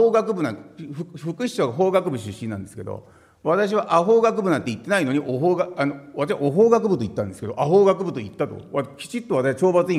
資料3　井桁議員が行った計4回の懲罰に対する弁明　音声③　（音声・音楽：120KB）